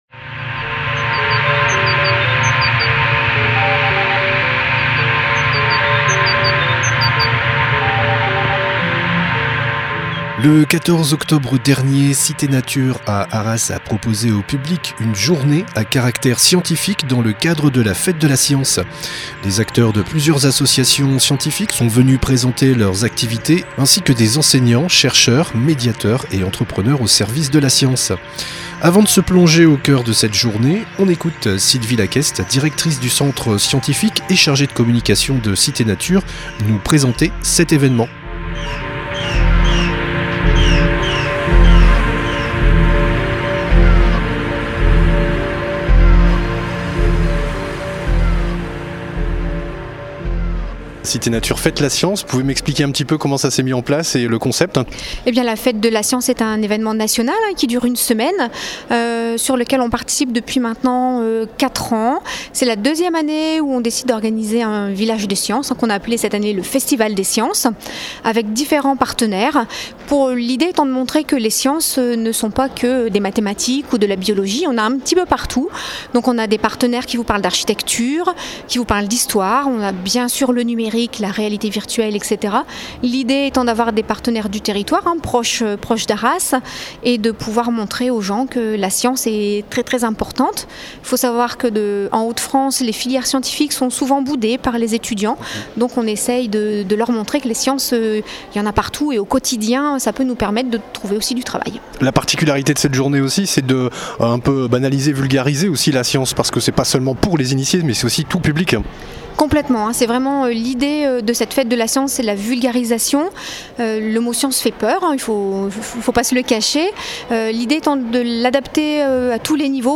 CITÉ NATURE FÊTE LA SCIENCE REPORTAGES/ENTRETIENS
Reportage au cœur de cet événement